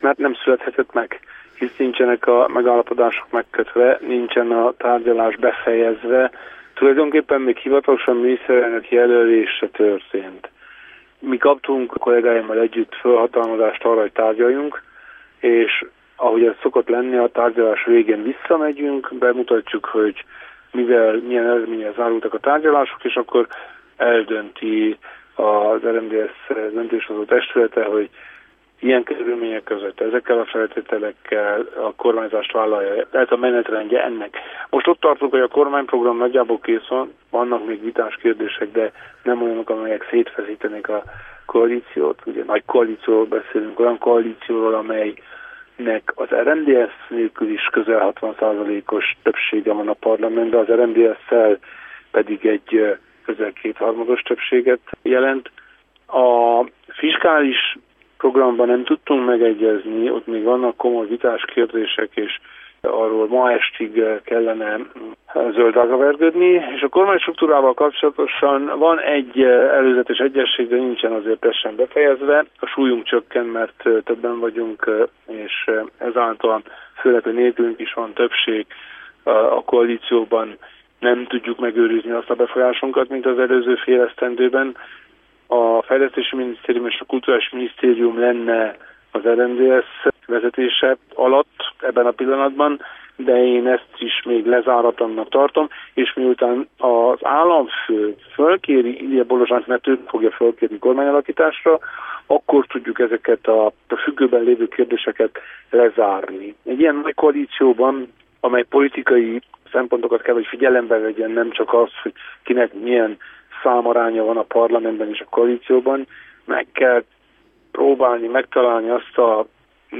Az államfővel való egyeztetés után kérdeztük az RMDSZ elnökét.